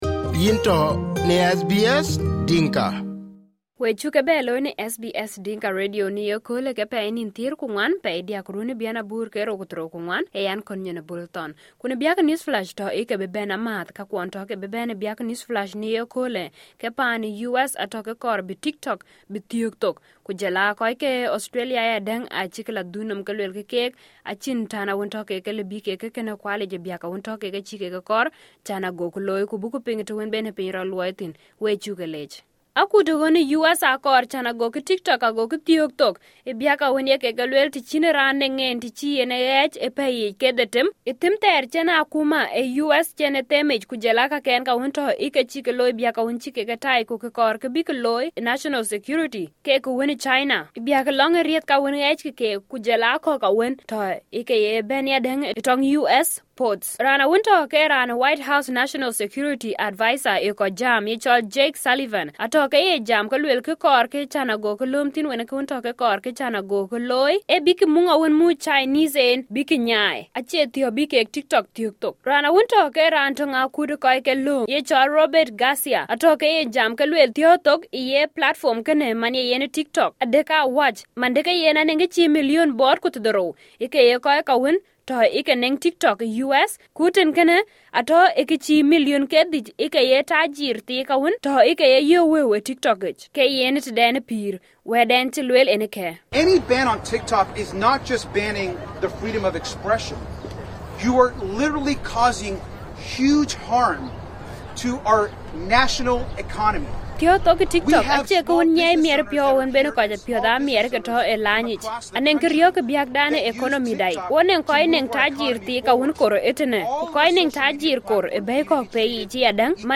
SBS Dinka News Flash 12/03/2024